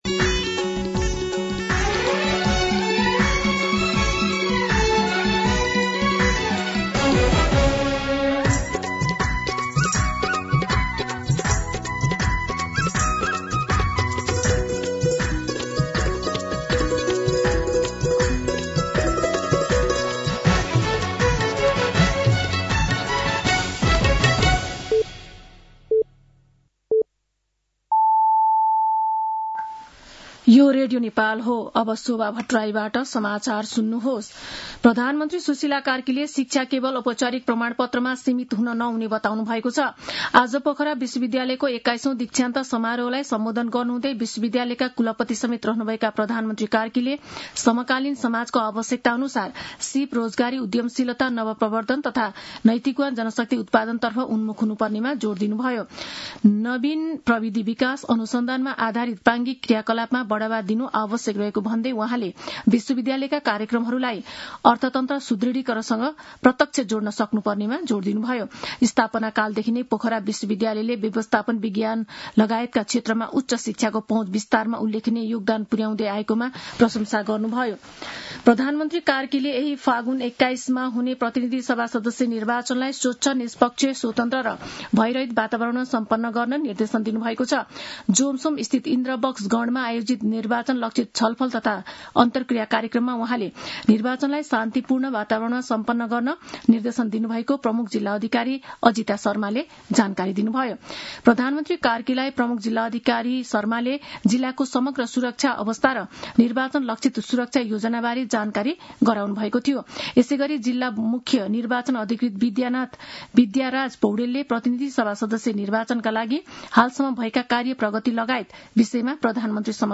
दिउँसो ४ बजेको नेपाली समाचार : ८ फागुन , २०८२